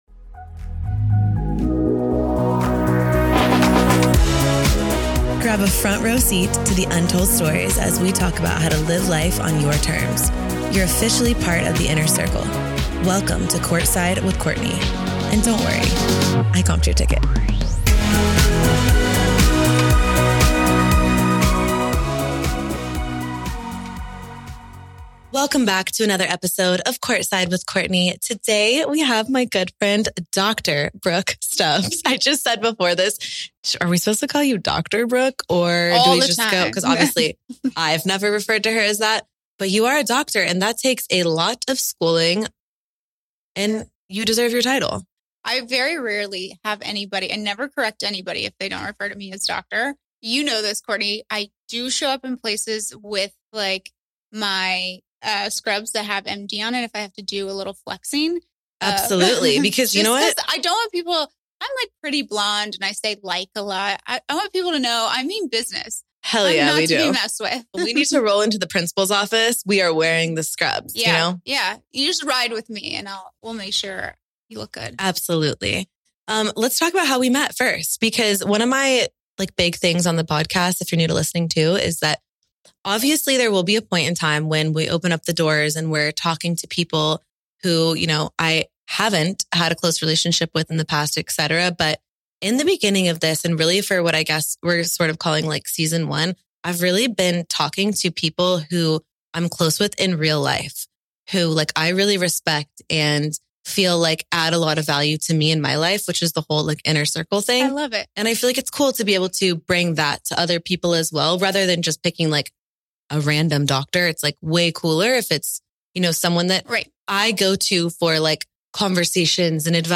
The two chat motherhood, confidence and friendships in adulthood.